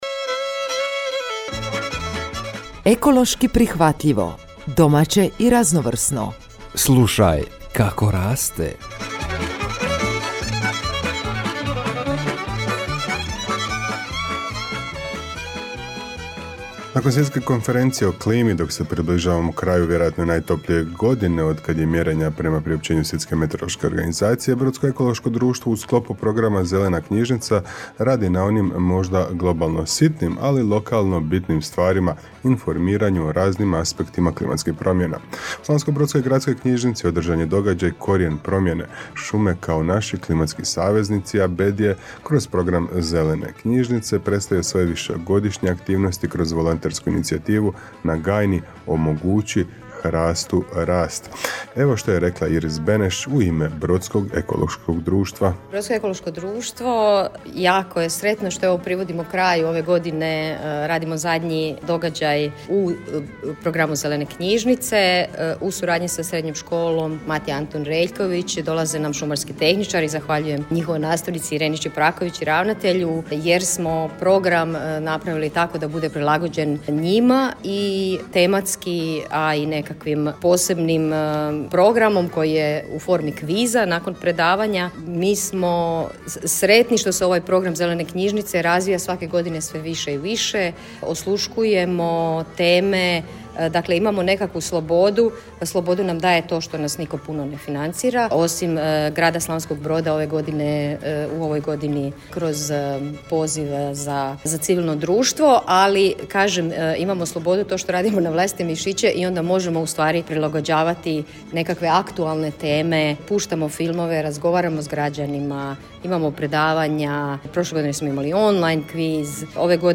stručnim suradnikom iz DOOR-a Ovaj edukativni sadržaj objavljujemo u obliku radijske emisije koju možete uvijek ponovno poslušati.